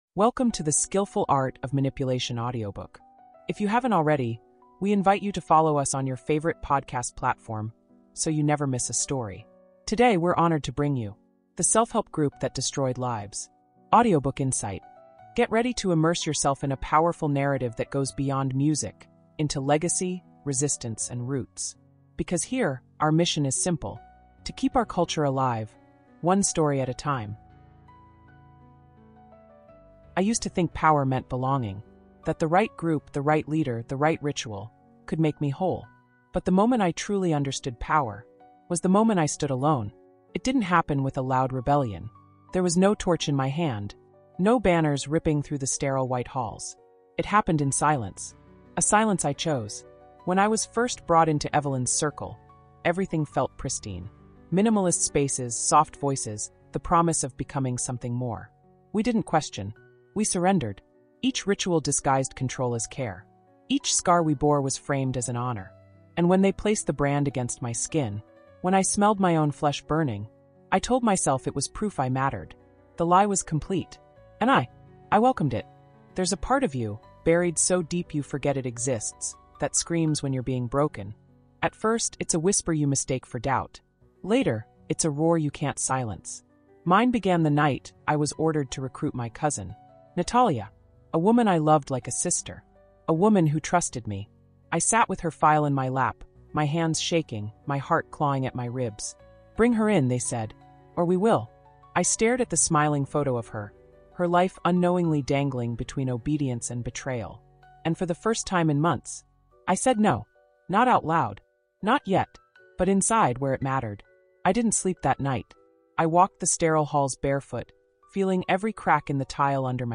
audiobook, true crime, cult story, self-help cult, psychological thriller, cult survivor, emotional abuse, manipulation, brainwashing, trauma recovery, escape from cult, personal transformation, survivor story, psychological abuse, cult experience, self-discovery, healing journey, cult psychology, mind control, emotional storytelling, true crime audiobook, cult insight, redemption story, psychological drama, cult narrative